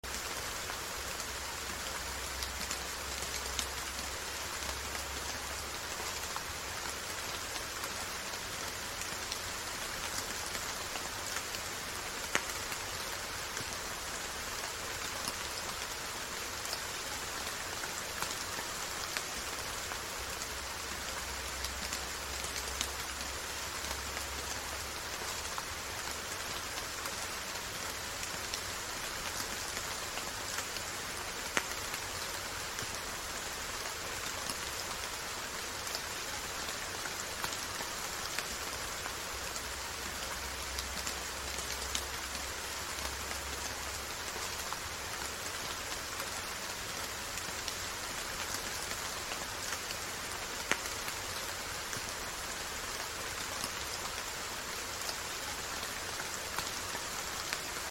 Rain
music_rain.BIeFvLvz.mp3